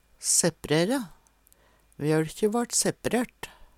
sepprere - Numedalsmål (en-US)